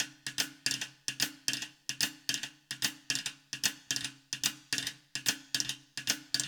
POWR RIM  -L.wav